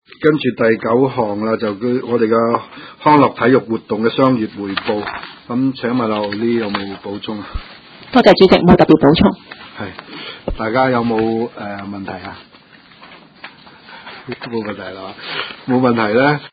灣仔民政事務處區議會會議室